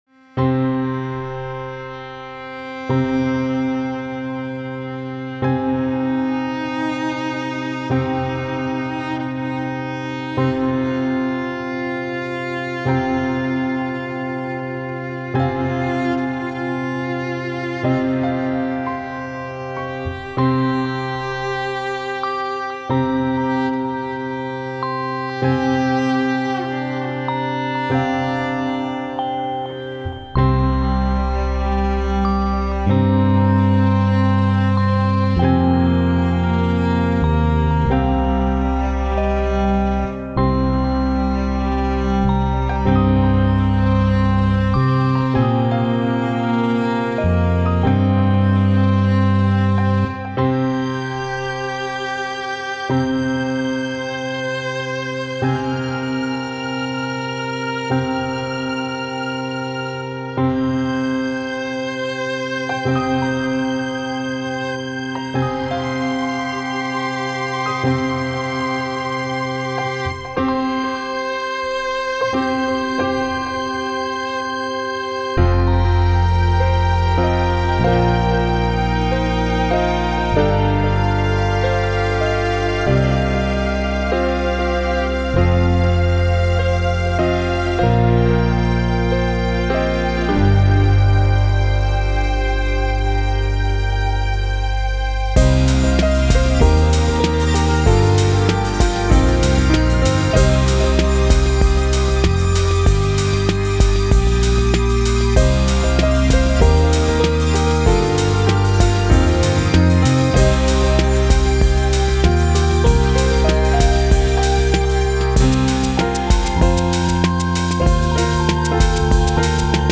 Everything in Transition (Electronic Chamber Music)